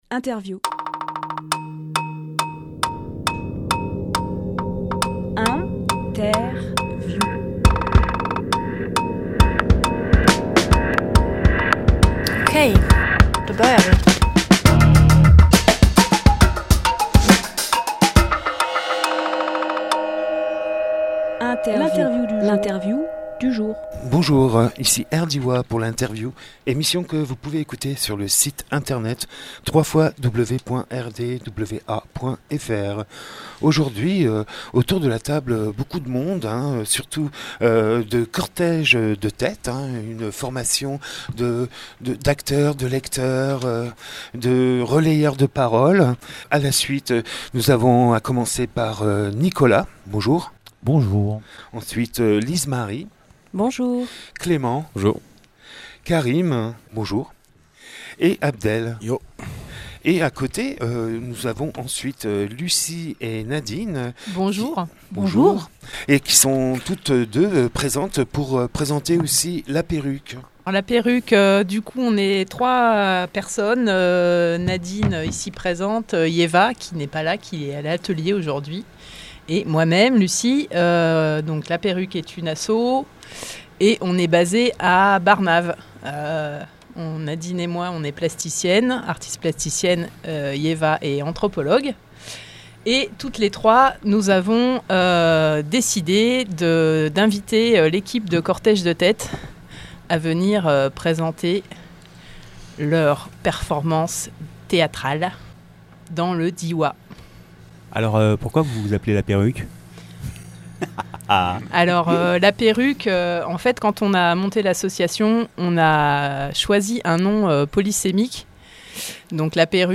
Emission - Interview Cortège de tête dans le Diois Publié le 4 octobre 2018 Partager sur…
Lieu : Studio RDWA